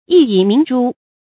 薏苡明珠 注音： ㄧˋ ㄧˇ ㄇㄧㄥˊ ㄓㄨ 讀音讀法： 意思解釋： 薏苡：多年生草本植物，果實可供食用釀酒，并入藥。